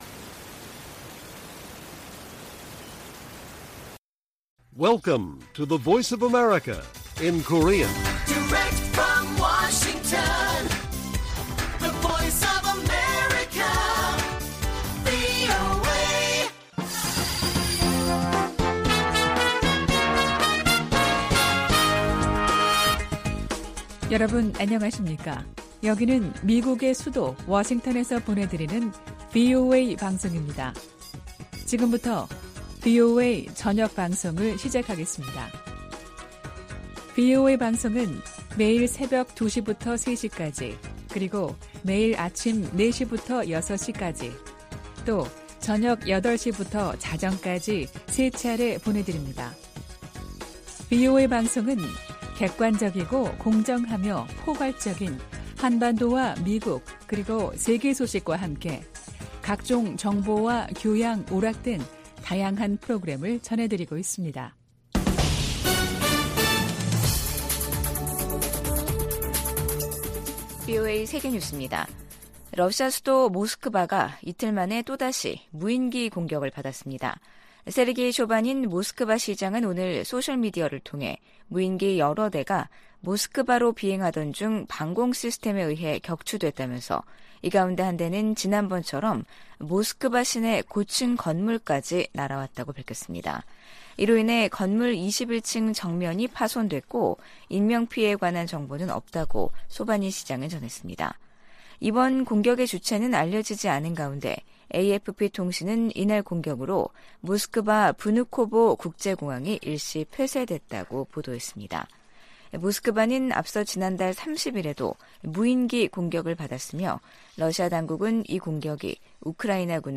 VOA 한국어 간판 뉴스 프로그램 '뉴스 투데이', 2023년 8월 1일 1부 방송입니다. 린다 토머스-그린필드 유엔 주재 미국대사가 북한의 식량 불안정 문제는 정권이 자초한 것이라고 지적했습니다. 제11차 핵확산금지조약(NPT) 평가회의 첫 준비 회의에서 주요 당사국들은 북한이 비확산 체제에 도전하고 있다고 비판했습니다. 북한과 러시아 간 무기 거래 가능성이 제기되는 데 대해 미국 국무부는 추가 제재에 주저하지 않을 것이라고 강조했습니다.